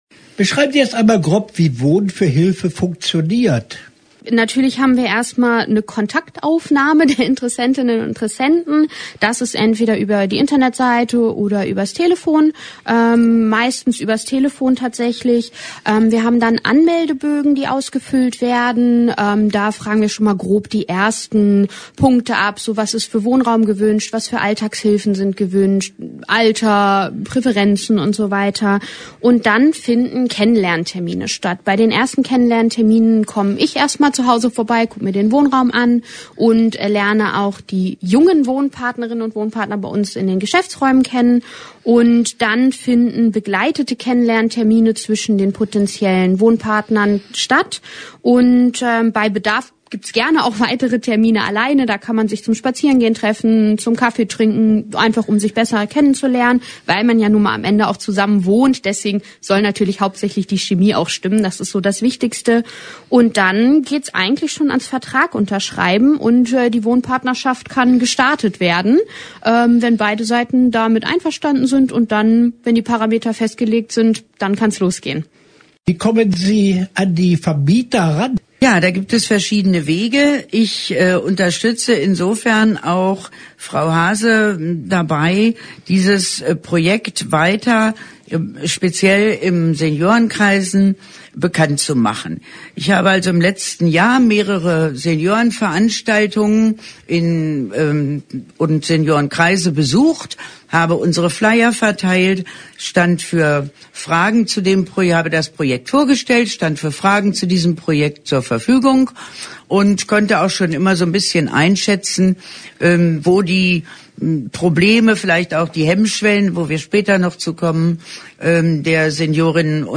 Interview-Wohnen-fuer-Hilfe.mp3